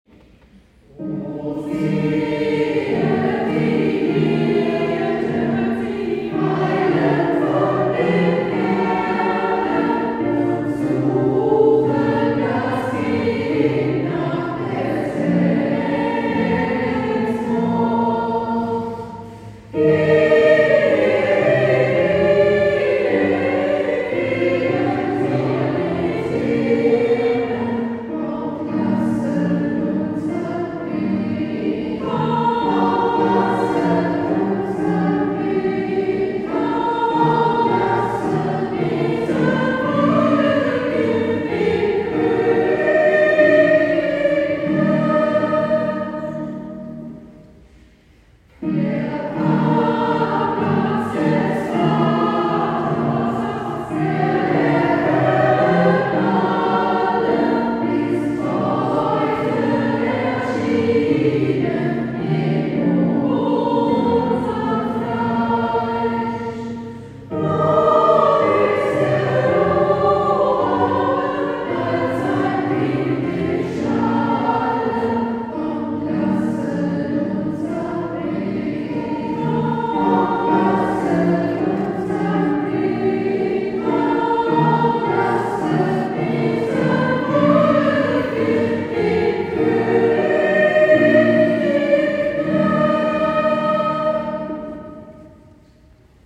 Am gestrigen Sonntag, 29. Dez. waren wieder viele Besucher zum „Offenen Singen zur Weihnachtszeit“ in die Peckelsheimer Pfarrkirche gekommen.
ihr Christen“ in einer mehrstimmigen Version zu Gehör.
PH24_Offenes-Singen-Laudate.m4a